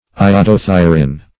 Iodothyrin \I`o*do*thy"rin\, n. [Iodo- + thyro- + -in.]